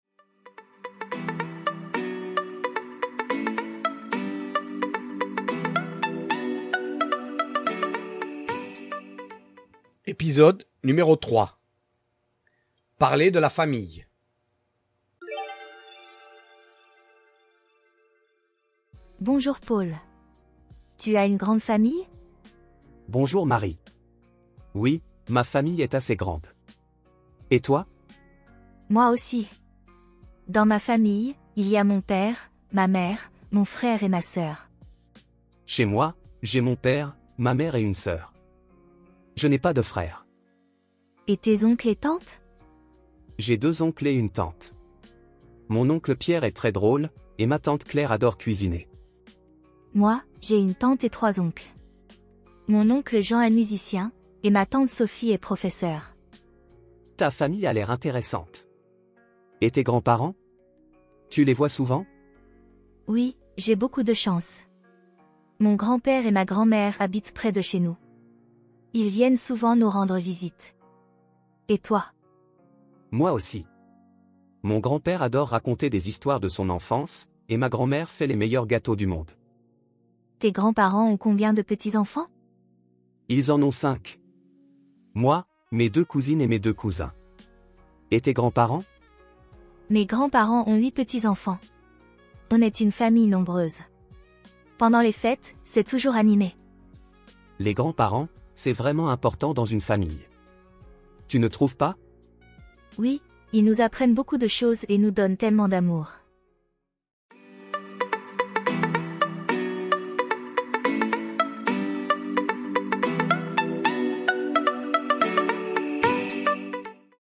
Voici un petit dialogue pour les débutants. Avec cet épisode, vous allez apprendre les membres de la famille.